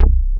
HARD C2.wav